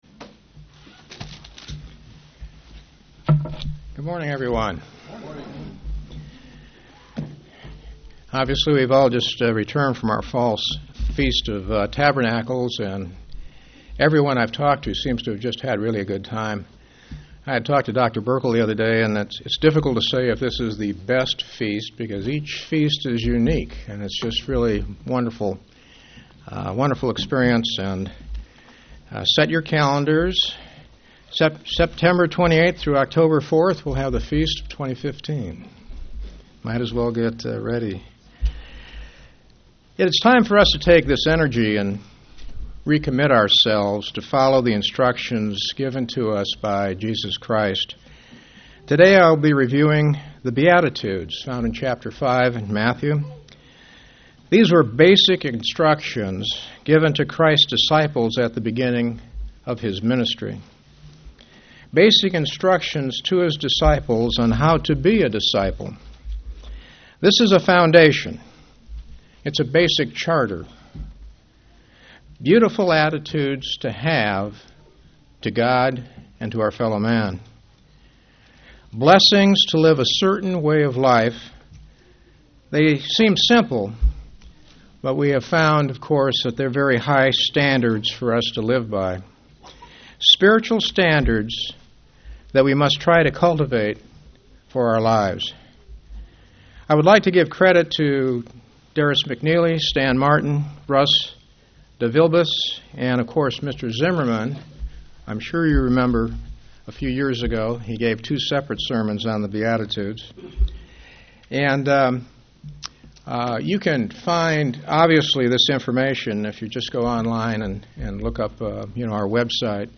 UCG Sermon Studying the bible?
Given in St. Petersburg, FL